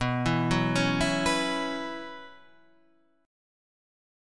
B Suspended 2nd Suspended 4th